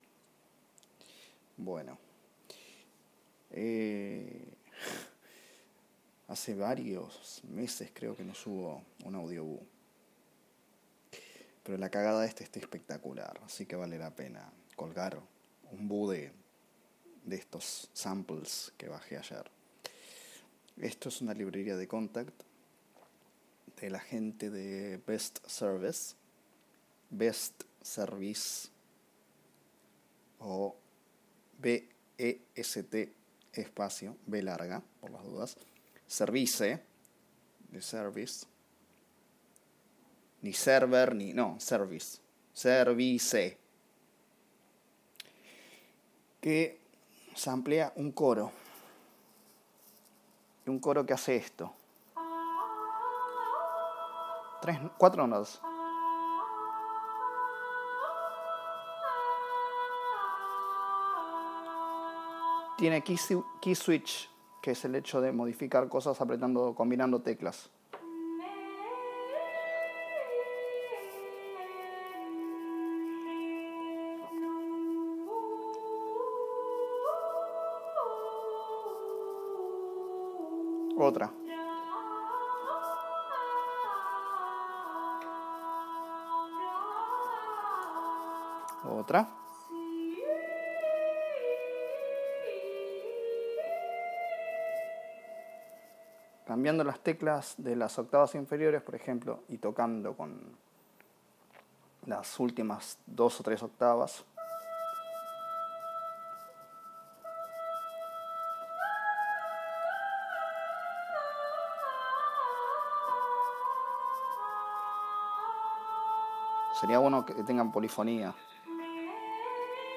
Demo de un coro de best service